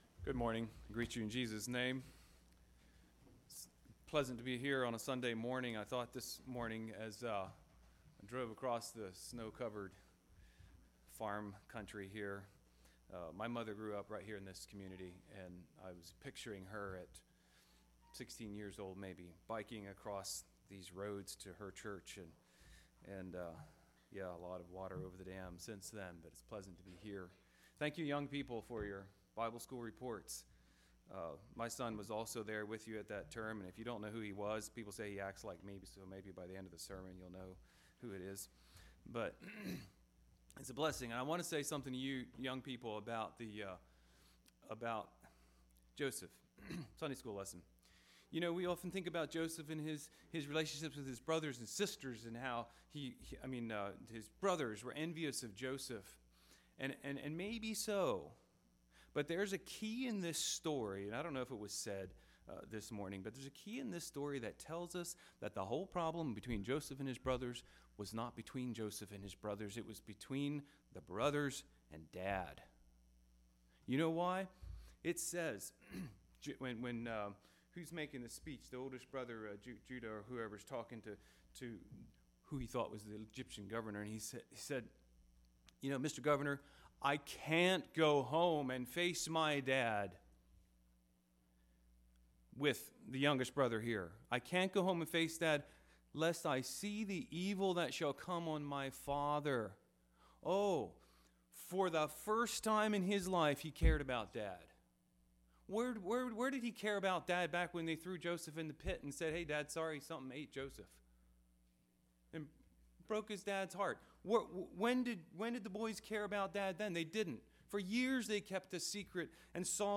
Sermons
Life in Christ | Bible Conference 2024